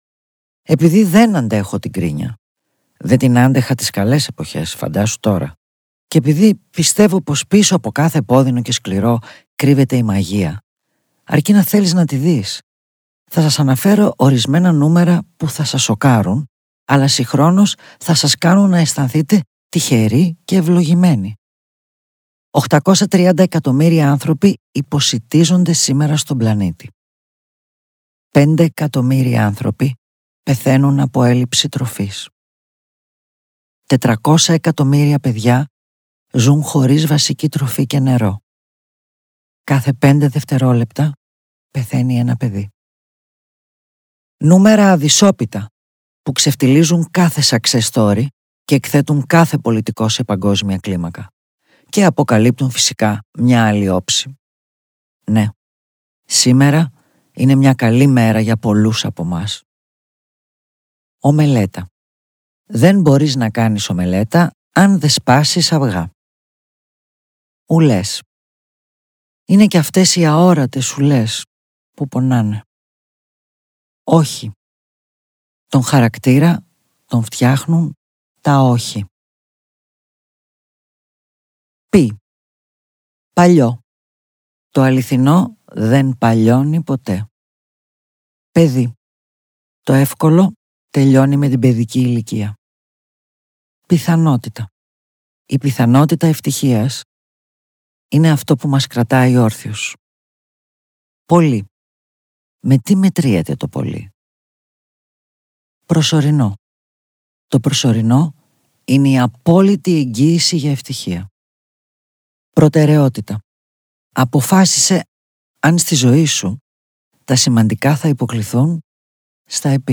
• Αυθεντική αφήγηση από την ίδια τη συγγραφέα.
Η ζεστή φωνή της συγγραφέως μεταδίδει αυθεντικά το μήνυμα της χαράς και λειτουργεί σαν προσωπική πρόσκληση να συνεχίσεις το ταξίδι ακρόασης.